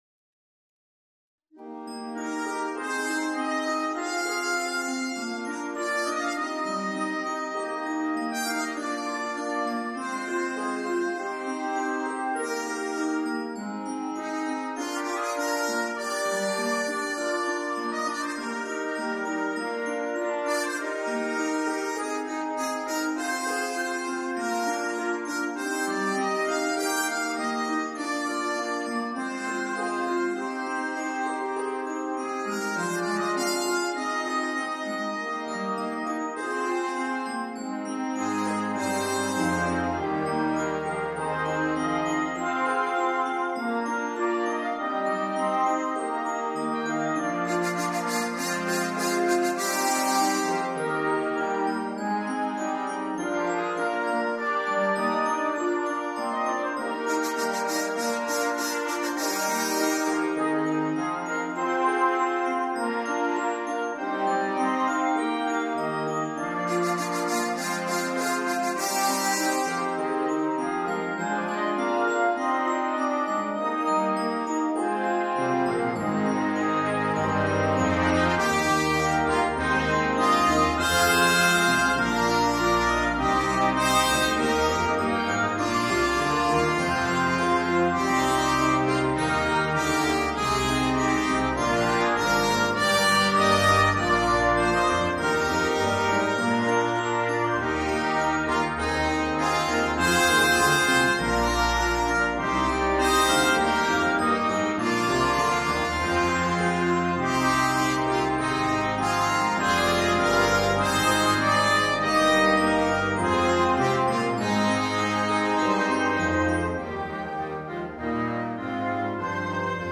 A hymnlike composition for Concert Band
The MP3 was recorded with NotePerformer 3.